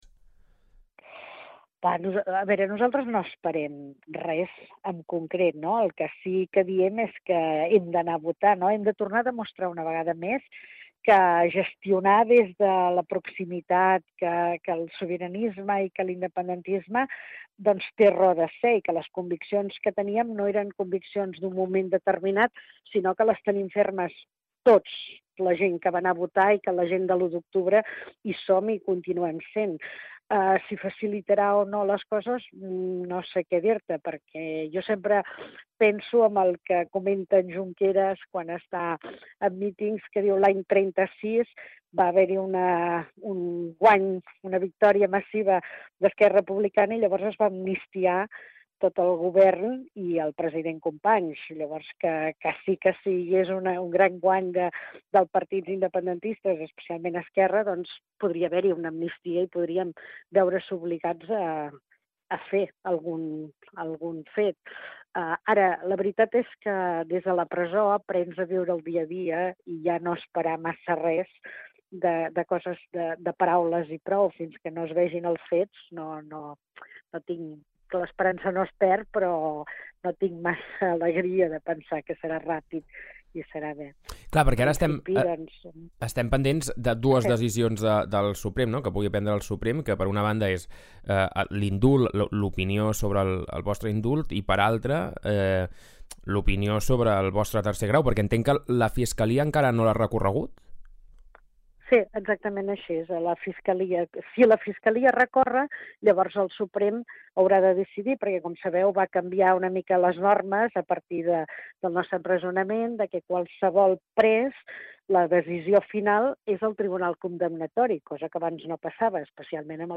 Entrevistes Supermatí
A l’entrevista del dimarts 9 de febrer vam parlar amb l’exconsellera Dolors Bassa, sobre com està passant el tercer grau i com encara la jornada electoral del 14 de Febrer.